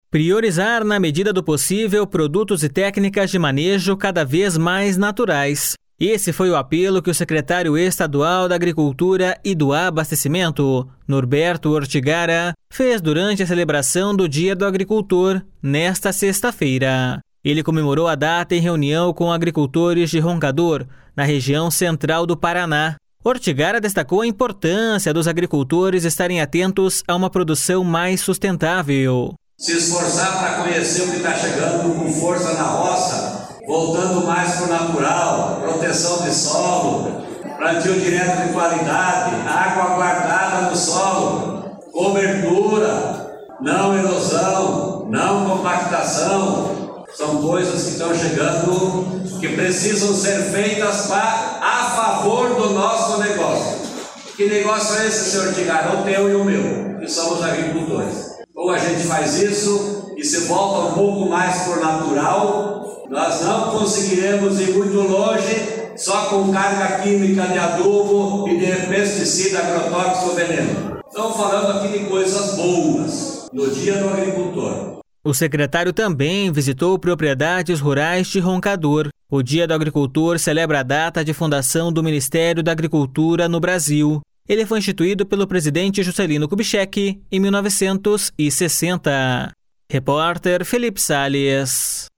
Ele comemorou a data em reunião com agricultores de Roncador, na região Central do Paraná. Ortigara destacou a importância dos agricultores estarem atentos a uma produção mais sustentável.// SONORA NORBERTO ORTIGARA.//